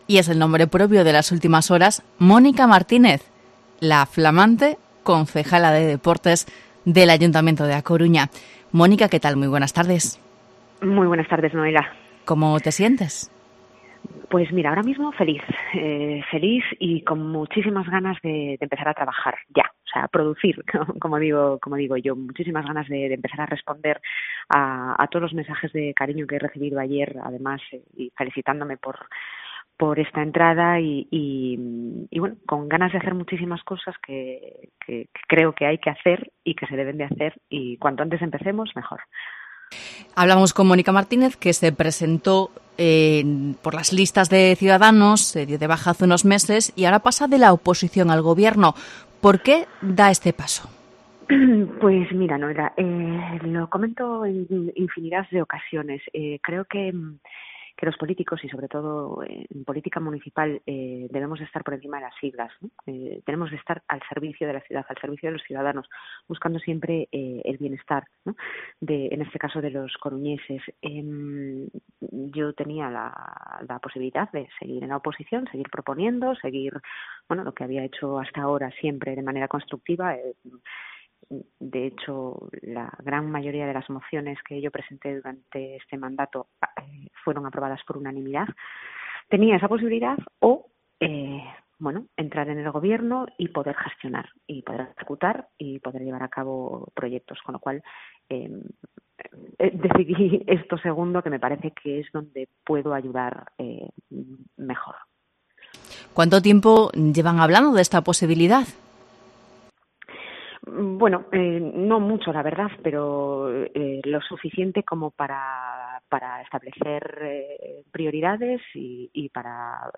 Entrevista a Mónica Martínez, concejala de Deportes de A Coruña